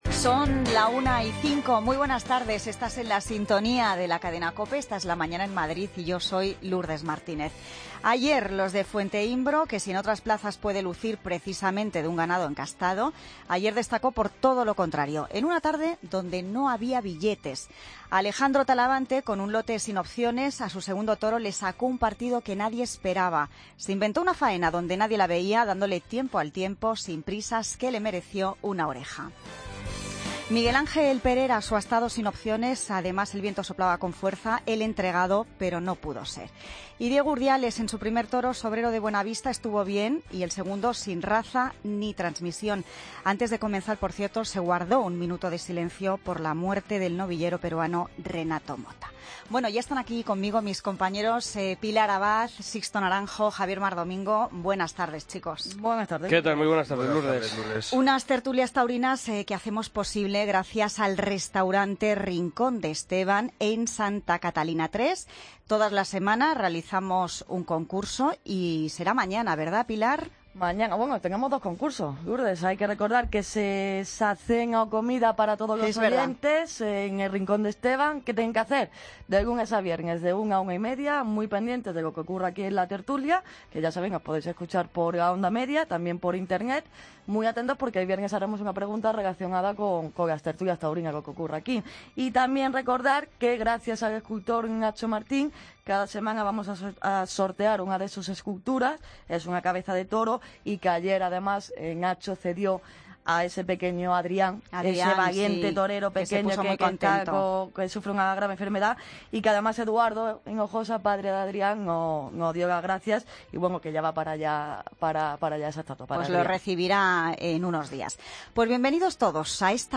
Tertulia Taurina Feria San Isidro COPE Madrid, jueves 19 de mayo de 2016